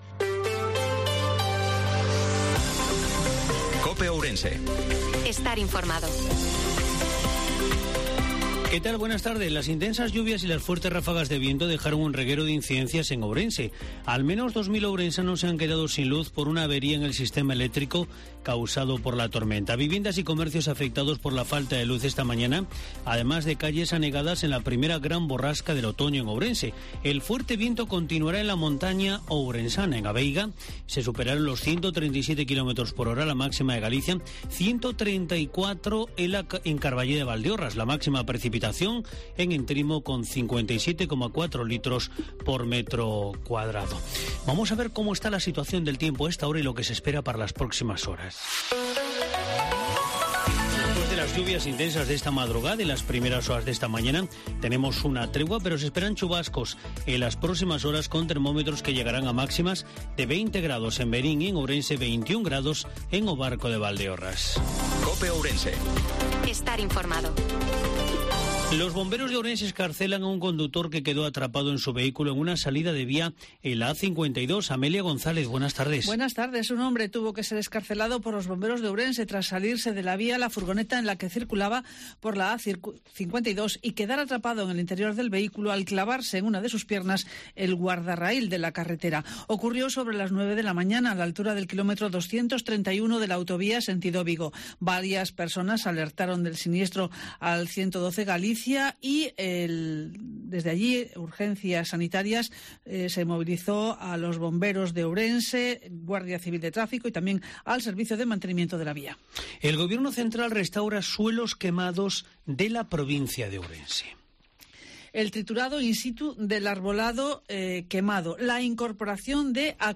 INFORMATIVO MEDIODIA COPE OURENSE-19/10/2022